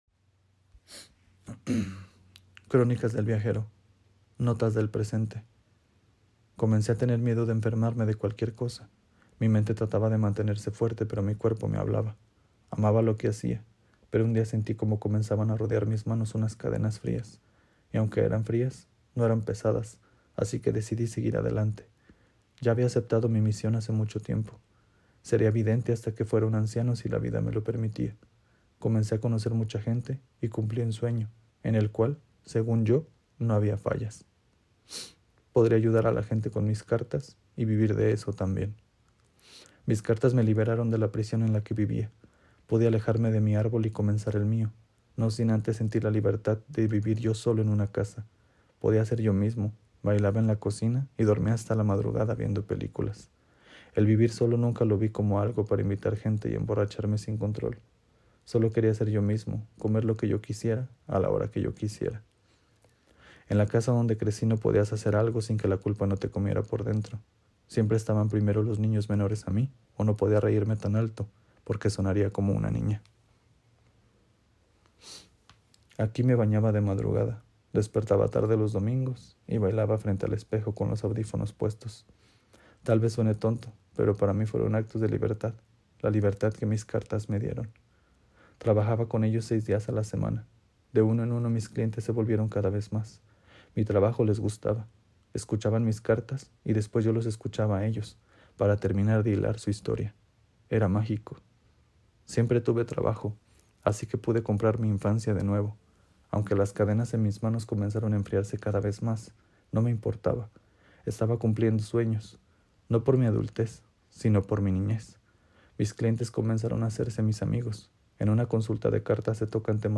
Estos audios son notas de voz actuales, te cuento mi pasado pero también siento la necesidad de contarte mi presente. Sin sonidos ni edición, solo mis sentimientos viviendo encadenado a mis amadas cartas de tarot.